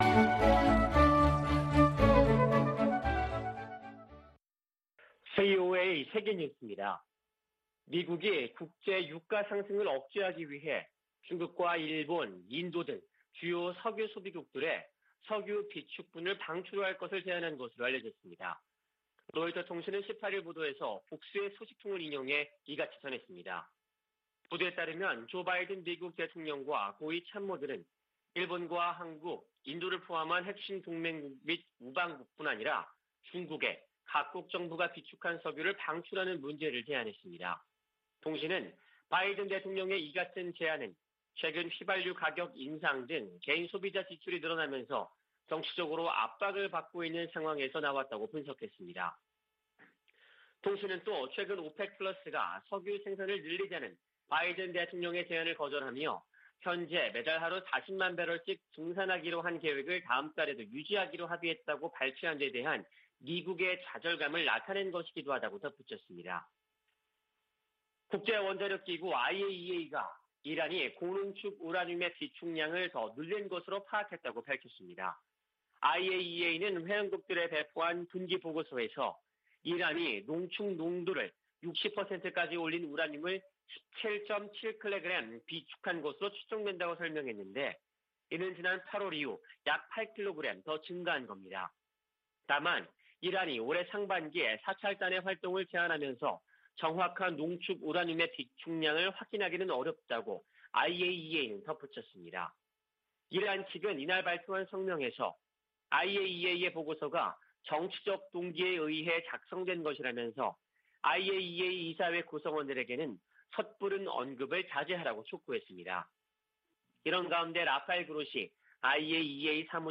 VOA 한국어 아침 뉴스 프로그램 '워싱턴 뉴스 광장' 2021년 11월 19일 방송입니다. 유엔총회 제3위원회가 북한 내 인권 침해에 대한 책임 추궁 등을 강조한 북한인권 결의안을 표결 없이 합의 채택했습니다. 미국 국무부가 북한을 종교자유 특별우려국으로 재지정했습니다.